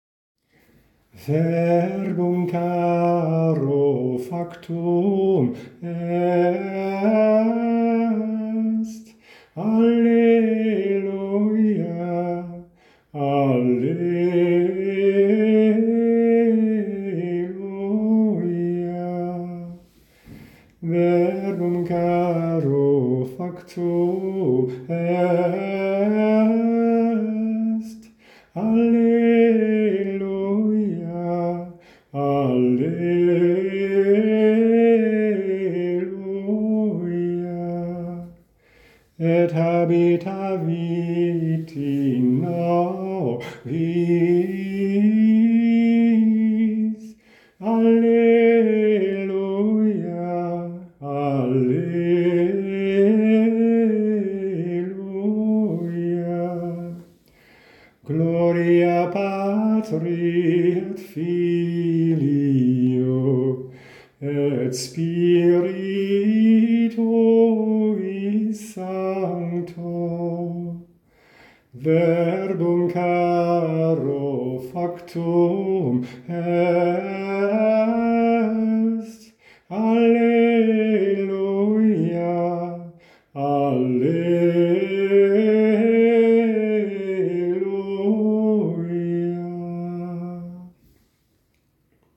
Gregoriaans